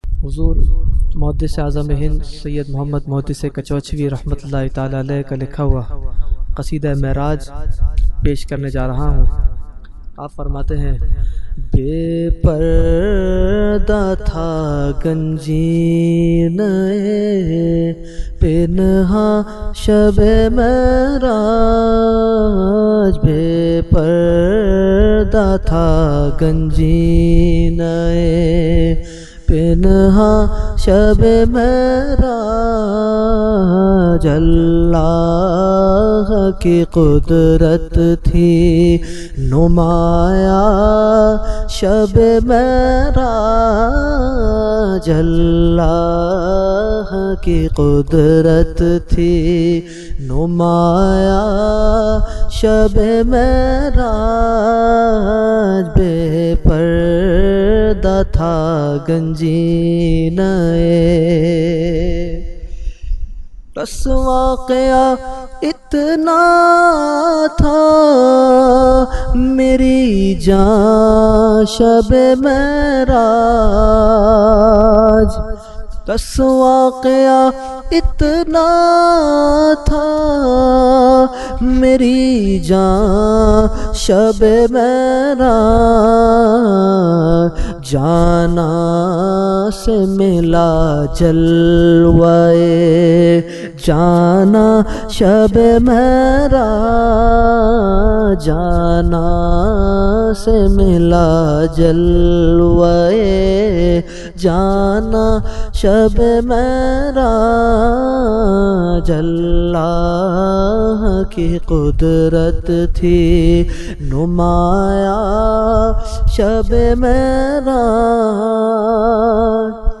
Category : Naat | Language : UrduEvent : Shab e Meraj 2020